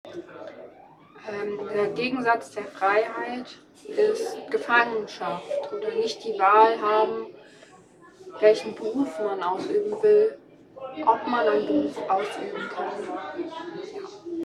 MS Wissenschaft @ Diverse Häfen
Der Anlass war MS Wissenschaft